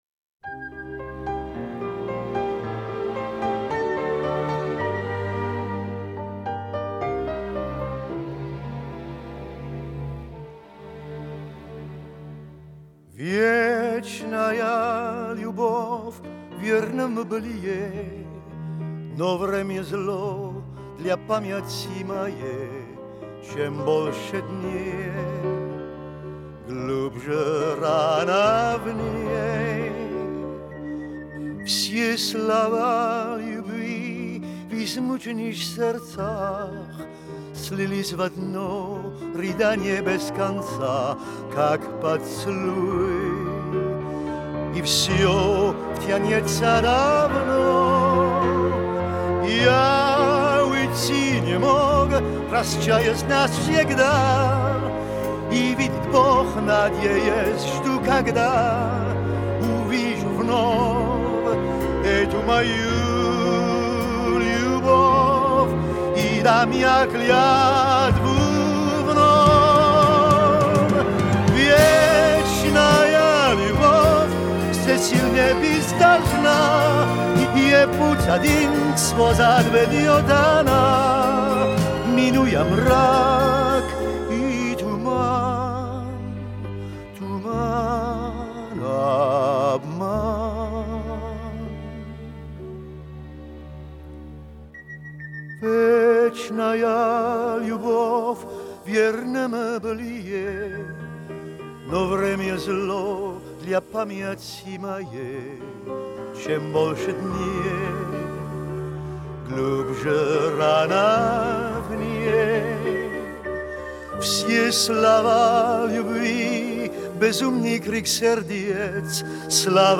Тема: песня
Но лучше автора её не смог спеть никто.